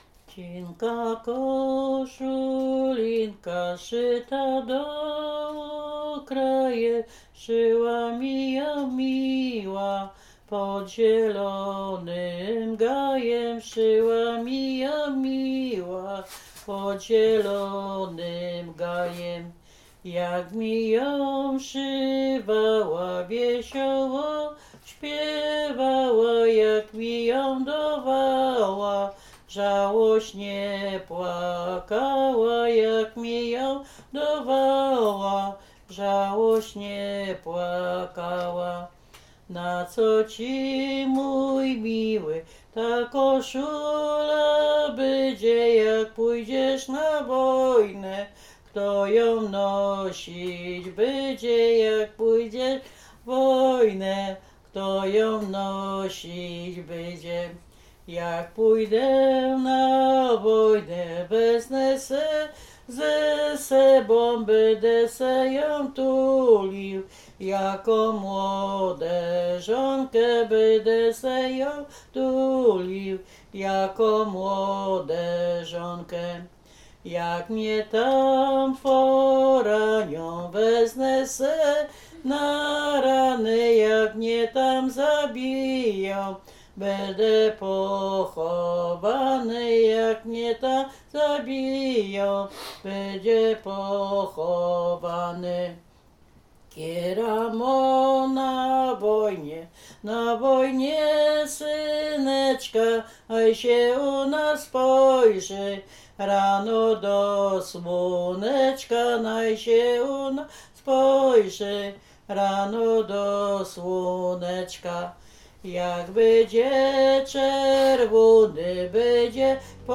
Bukowina Rumuńska
liryczne wojenkowe rekruckie